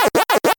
eating.wav